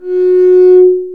Index of /90_sSampleCDs/Roland - String Master Series/STR_Cb Bowed/STR_Cb Harmonics